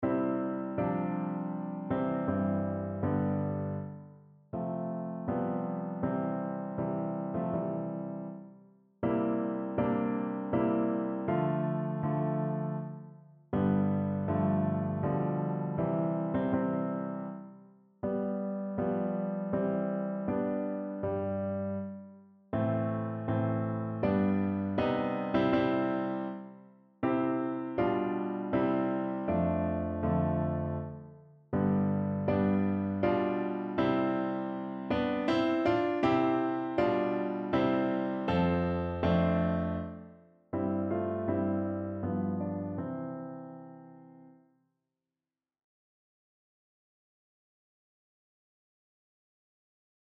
Evangeliumslieder
Notensatz (4 Männer)